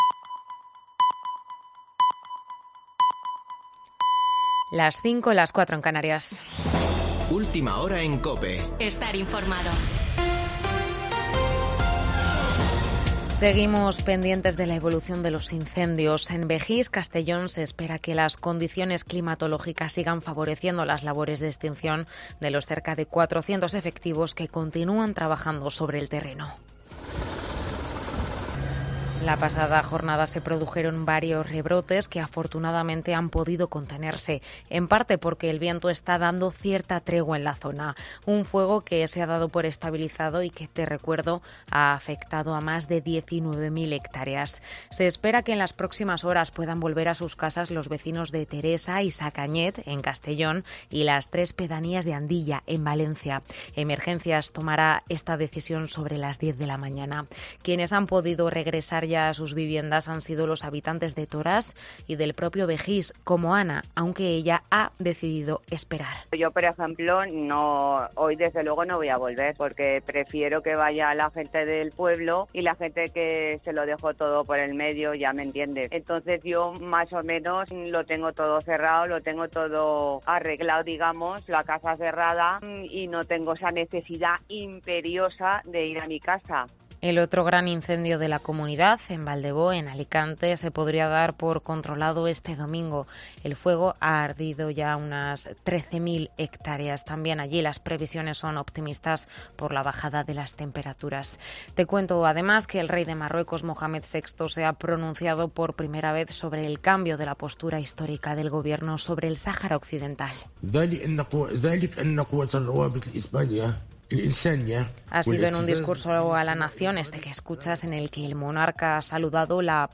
Boletín de noticias de COPE del 21 de agosto de 2022 a las 05.00 horas